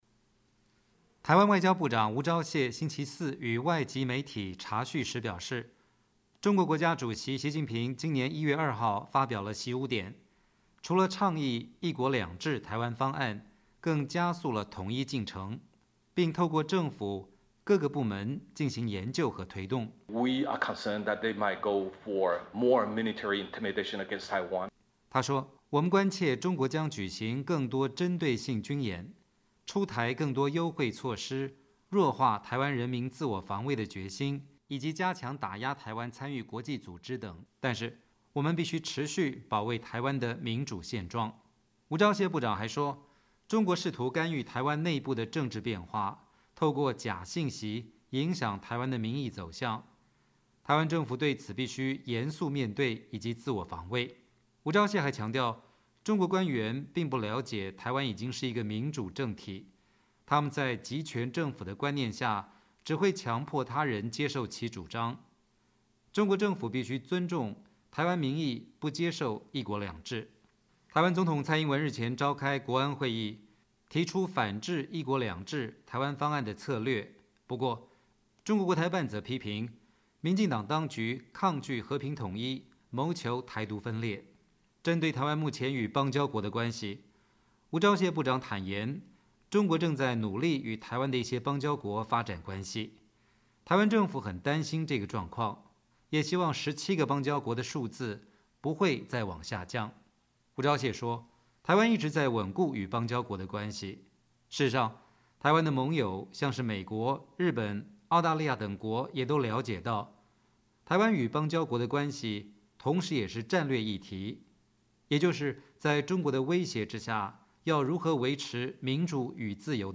台湾外长吴钊燮2019年3月14号与外籍媒体茶叙就当前情势发表看法。